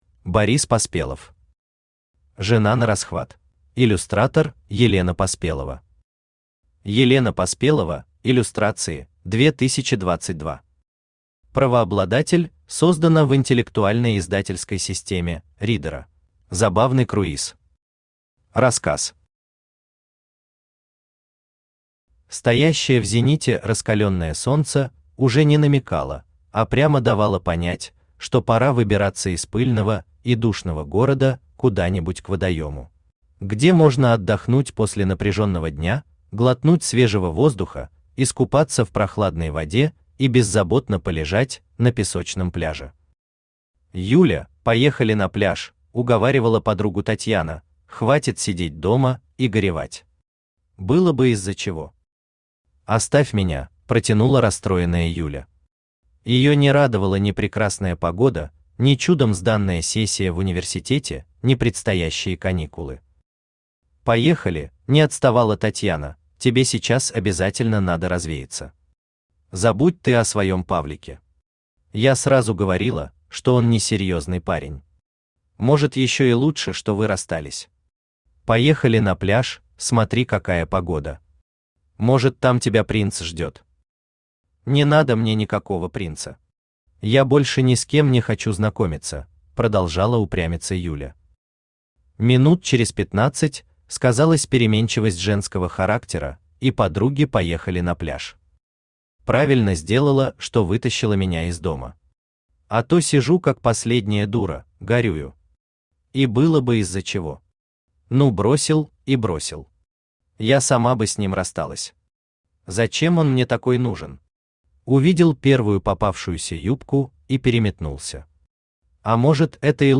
Аудиокнига Жена нарасхват | Библиотека аудиокниг
Aудиокнига Жена нарасхват Автор Борис Поспелов Читает аудиокнигу Авточтец ЛитРес.